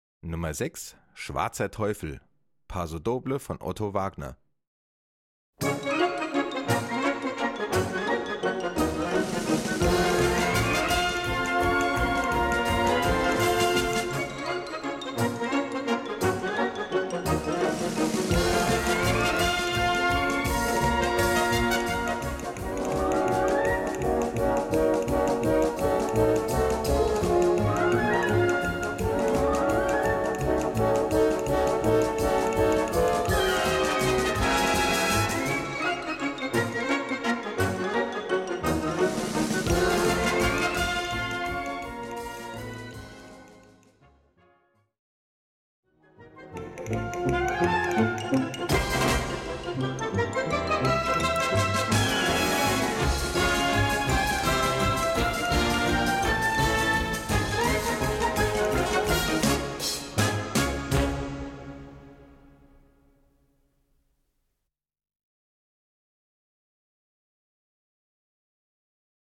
Gattung: Konzertstück
A4 Besetzung: Blasorchester Zu hören auf